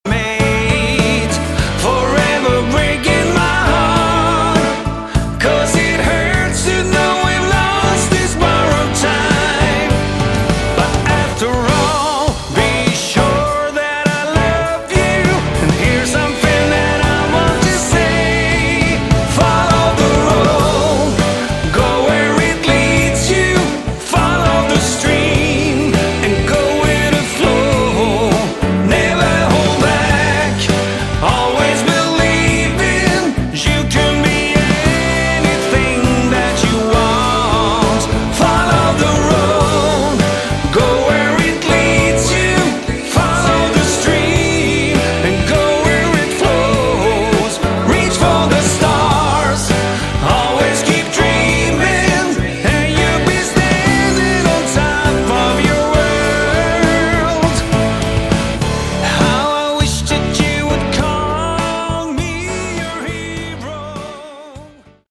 Category: AOR / Melodic Rock
vocals
guitars, bass, keys
keys, synths